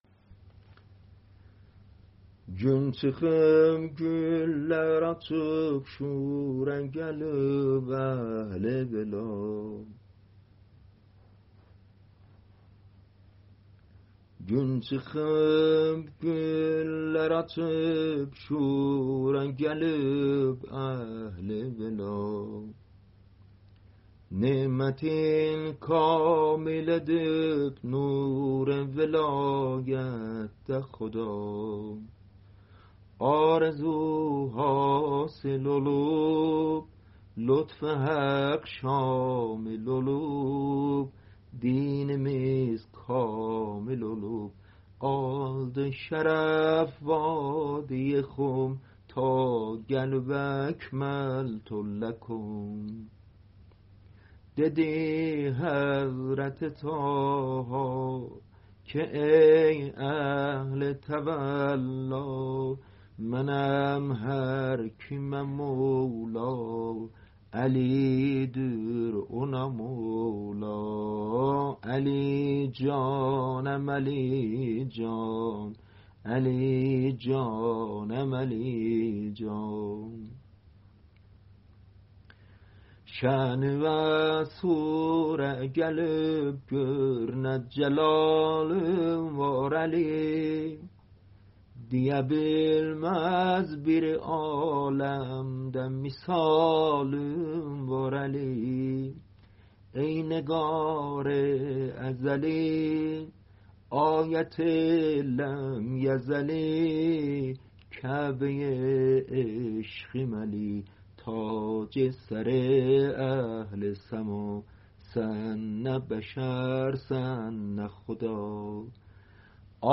غدیریه